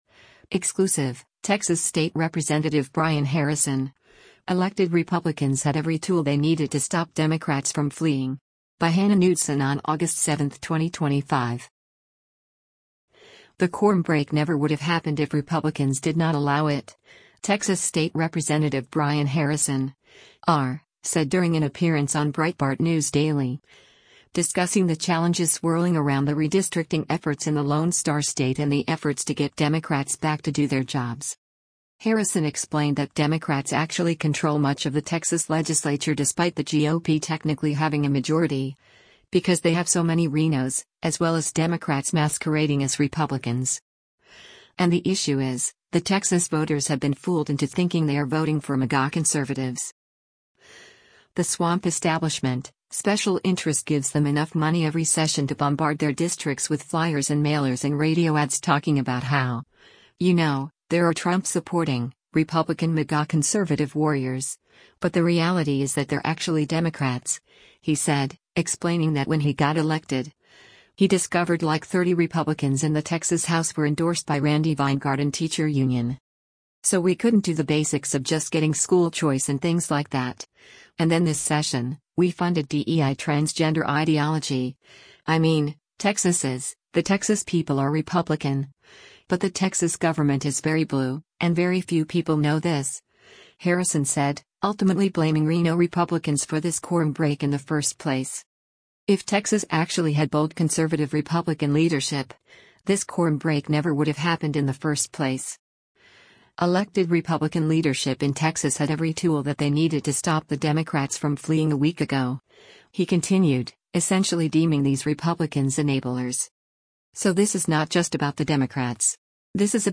The quorum break never would have happened if Republicans did not allow it, Texas State Rep. Brian Harrison (R) said during an appearance on Breitbart News Daily, discussing the challenges swirling around the redistricting efforts in the Lone Star State and the efforts to get Democrats back to do their jobs.
Breitbart News Daily airs on SiriusXM Patriot 125 from 6:00 a.m. to 9:00 a.m. Eastern.